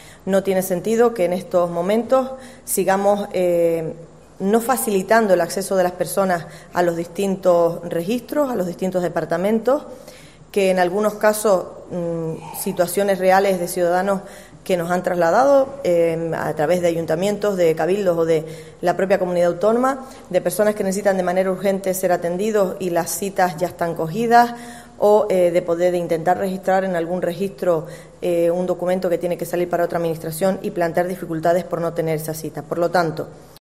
Nieves Lady Barreto, consejera de Presidencia, Administraciones Públicas, Justicia y Seguridad